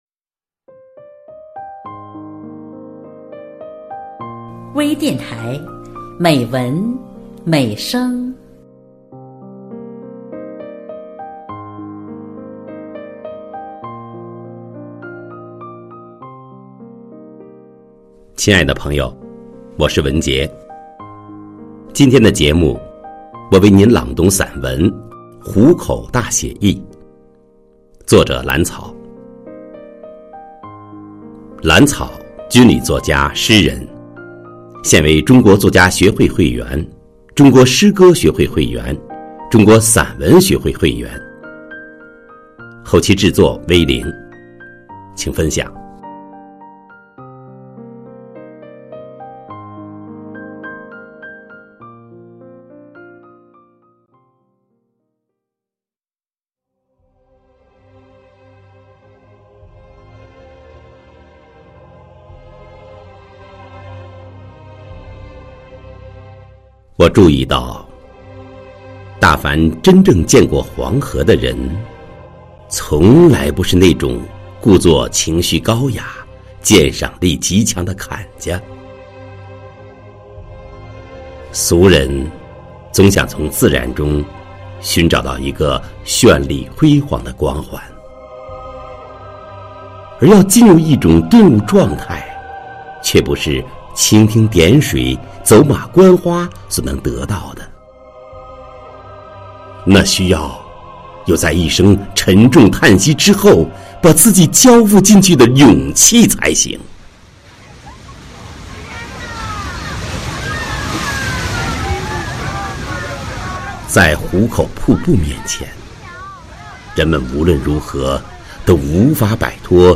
专业诵读 精良制作